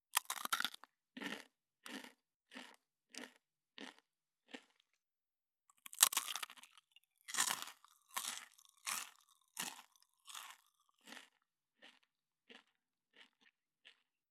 9.スナック菓子・咀嚼音【無料効果音】
ASMR